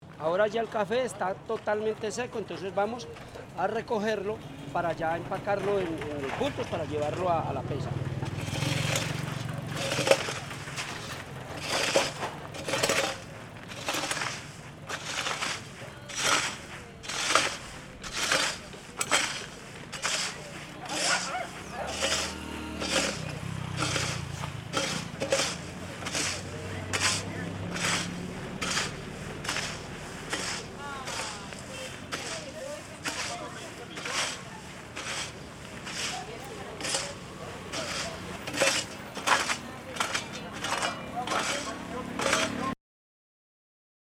Registro sonoro del proceso de producción de café en Trujillo, Valle del Cauca. Fase de recolección del café.